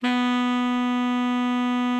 bari_sax_059.wav